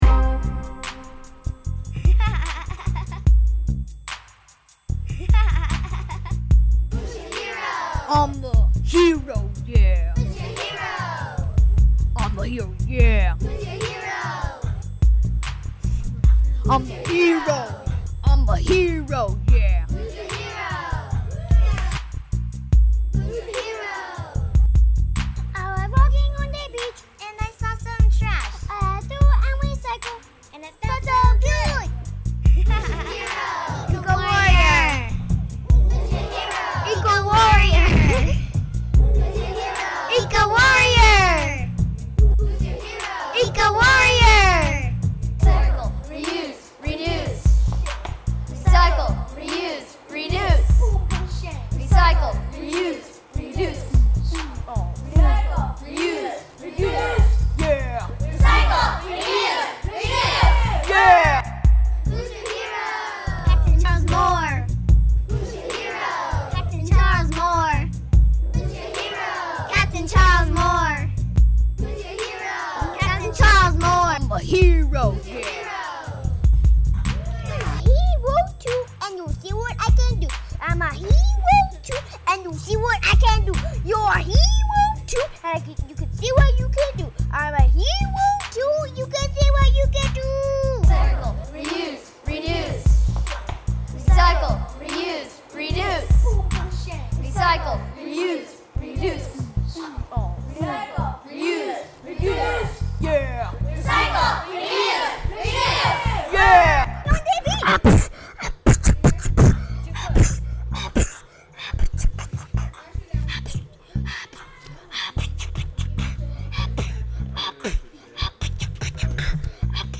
Laguna Beach Boy's and Girl's Club
Hero Rap.mp3